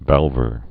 (vălvər)